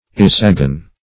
Search Result for " isagon" : The Collaborative International Dictionary of English v.0.48: Isagon \I"sa*gon\ ([imac]"s[.a]*g[o^]n), n. [Gr.